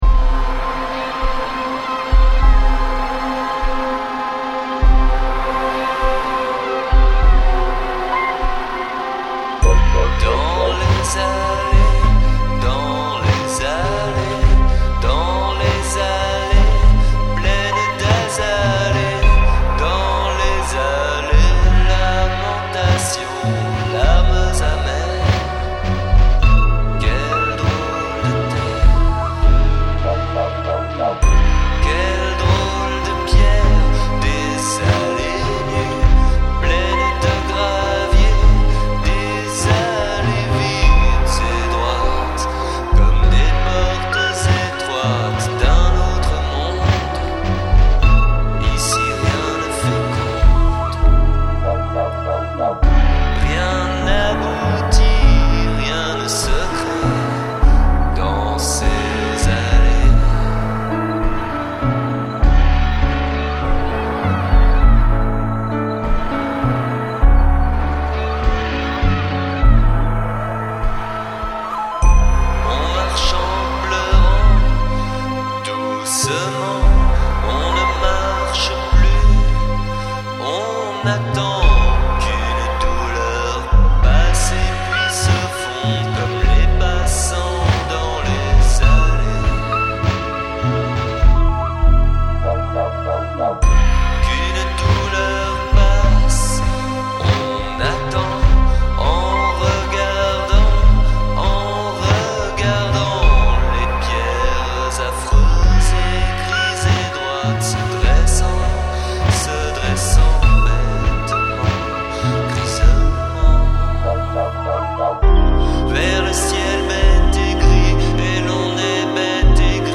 version feutrée
voix, piano, programmation